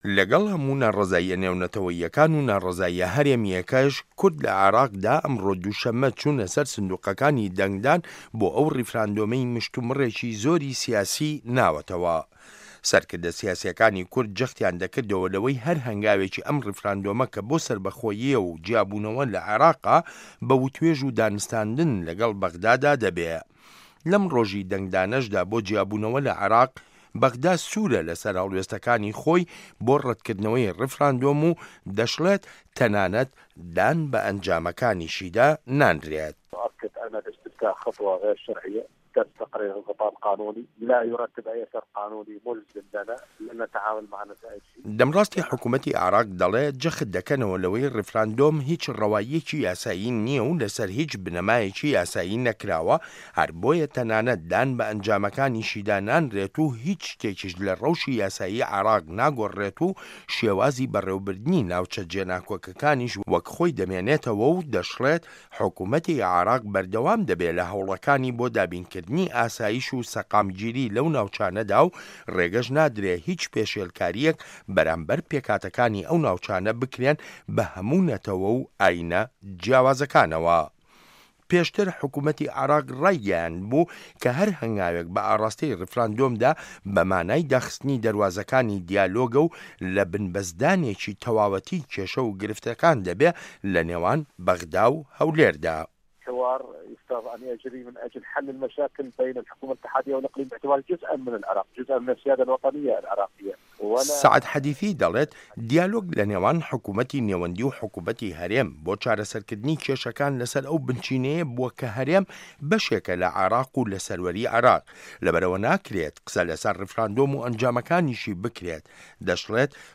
ڕاپۆرت لەسەر بنچینەی وتووێژ لەگەڵ دەمڕاستی حکومەتی عێراق